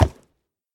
Sound / Minecraft / mob / horse / wood1.ogg